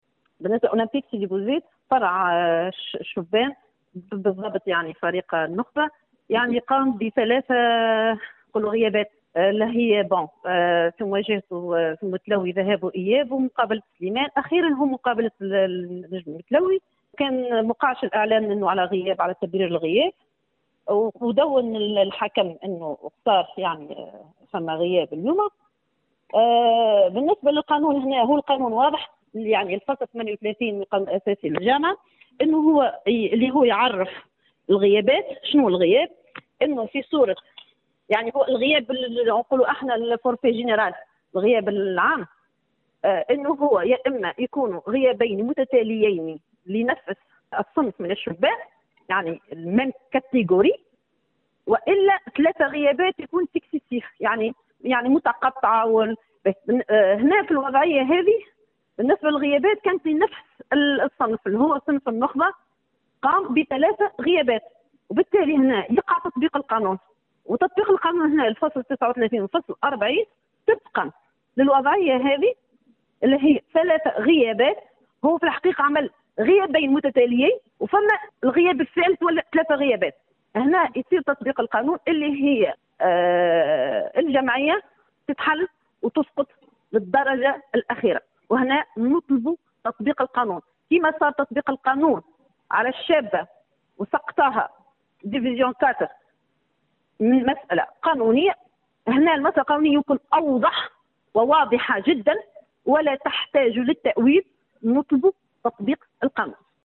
في تصريح خاص للجوهرة أف أم